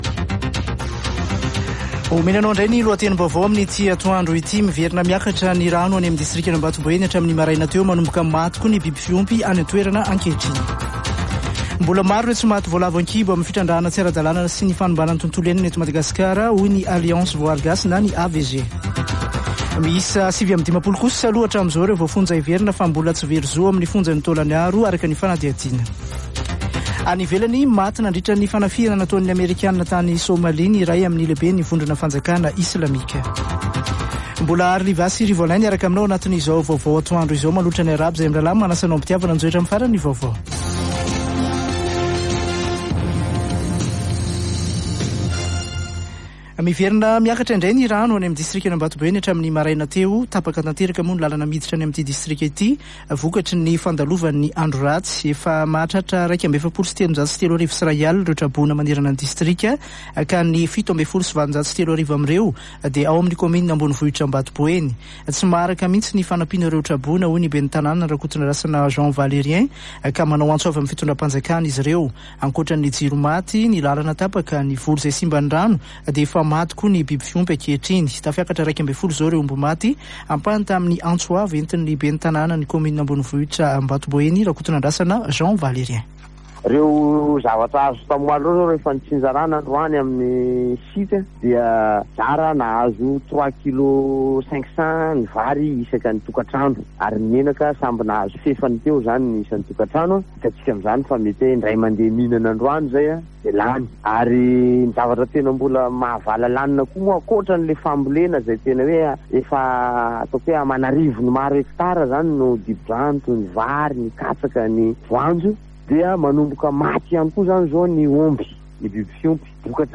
[Vaovao antoandro] Zoma 27 janoary 2023